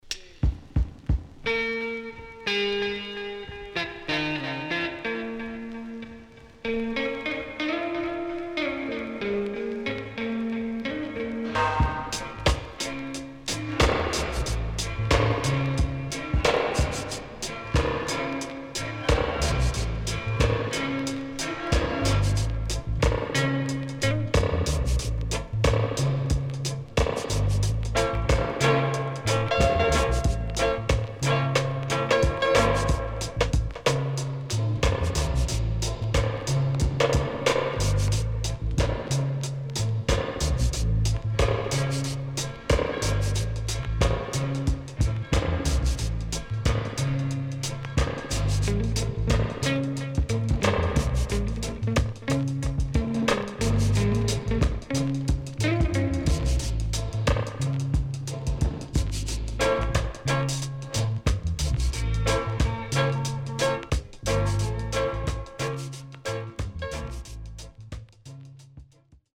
HOME > REGGAE / ROOTS  >  KILLER & DEEP  >  INST 70's
SIDE A:少しチリノイズ入ります。